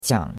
qiang3.mp3